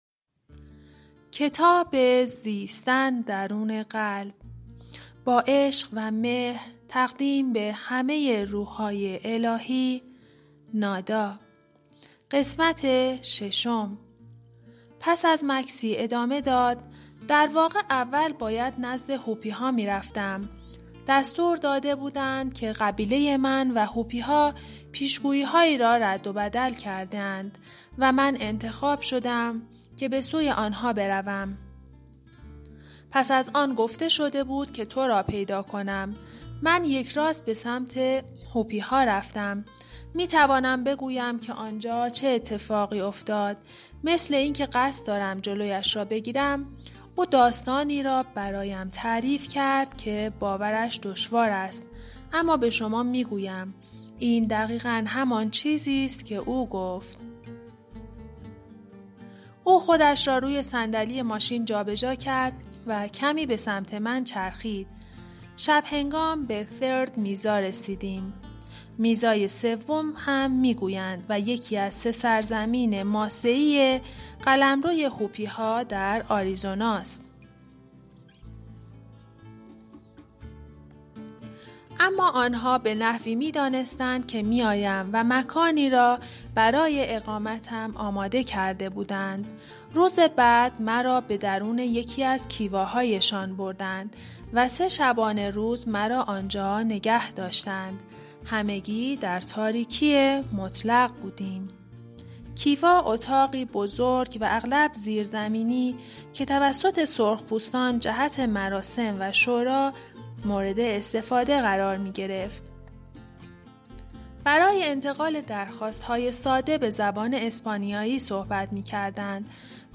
کتاب گویای زیستن درون قلب نویسنده درونوالو ملچیزدک / قسمت6